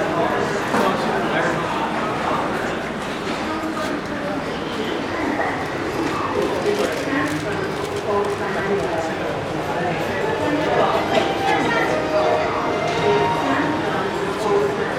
Scene_Noise_Data_by_Voice_Recorder